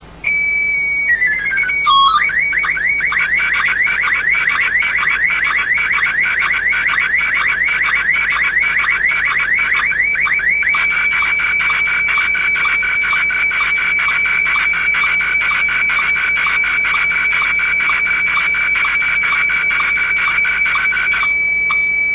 RealAudio FAX/SSTV Sounds
SSTV COLOR WRAASE 120 120 sec. 256